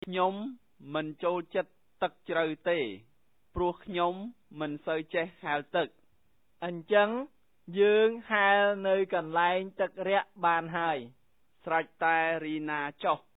Here is a clip of part of a conversation in an unknown language.
It sounds tonal.